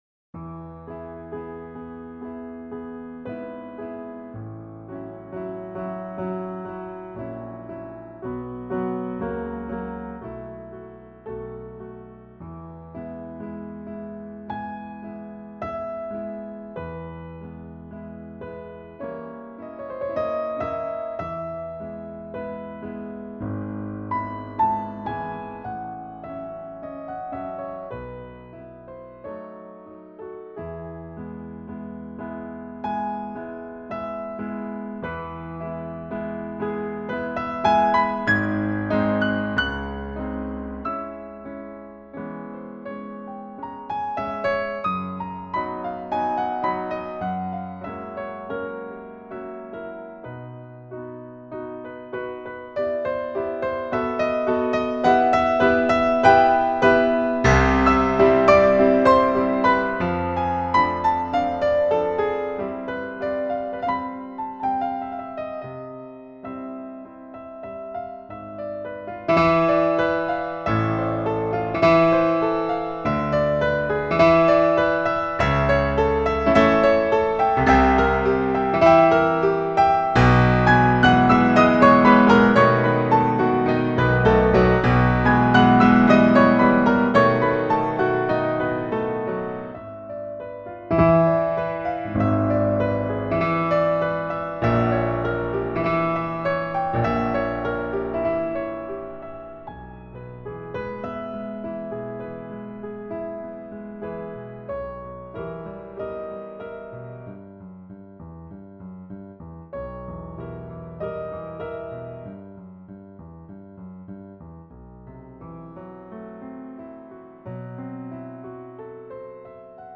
Bosendorfer---ivory.mp3